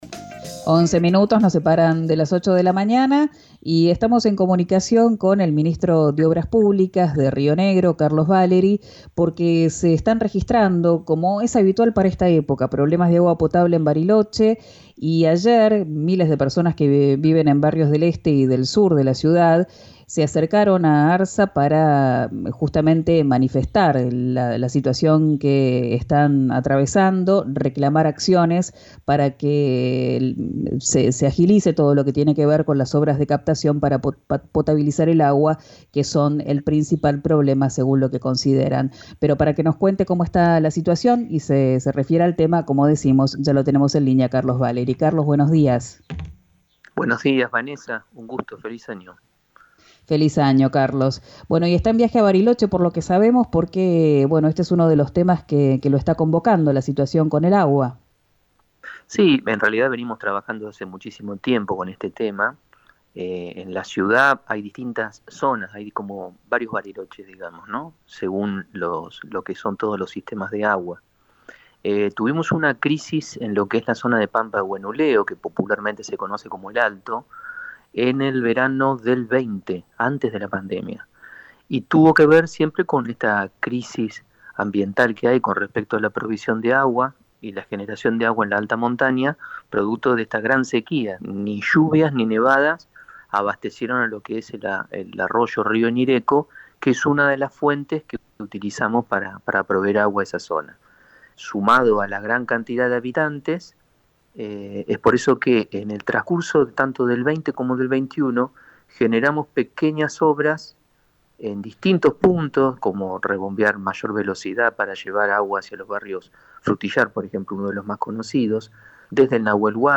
A esto se suma la gran cantidad de habitantes», dijo el funcionario provincial en «Quién dijo Verano» por RÍO NEGRO RADIO.